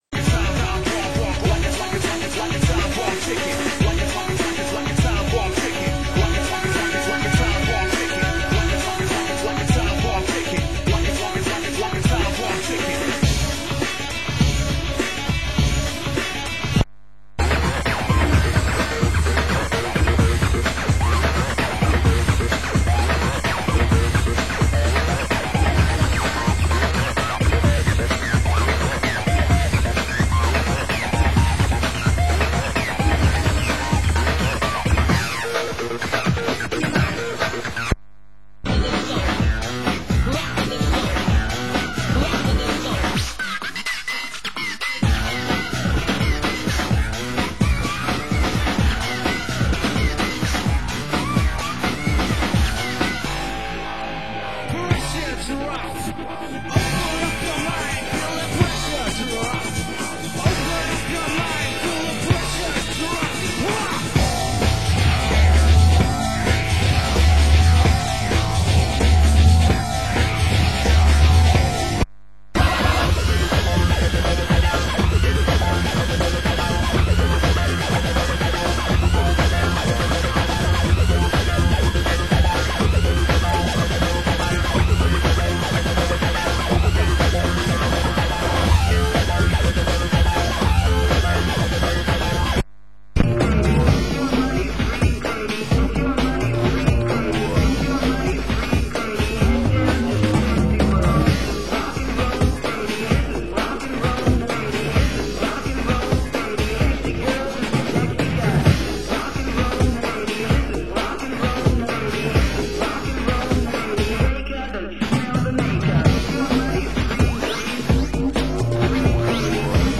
Genre: Electronica